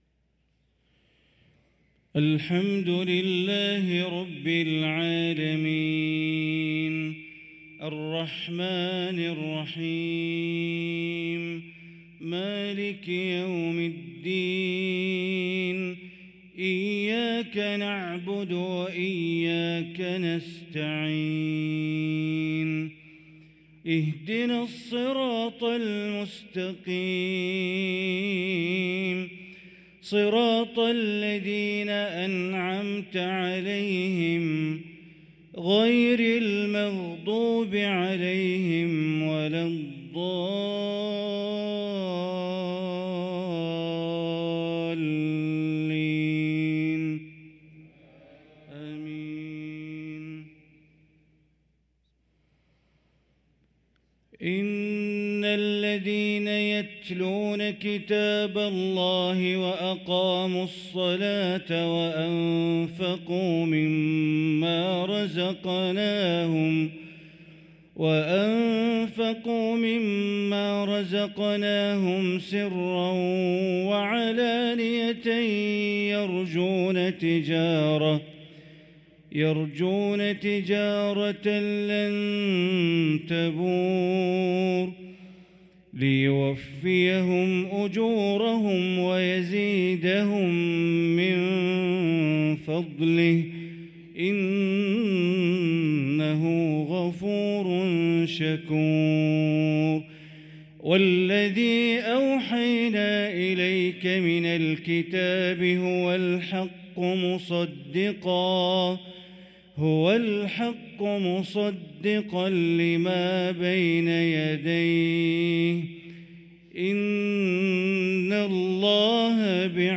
صلاة العشاء للقارئ بندر بليلة 26 ربيع الأول 1445 هـ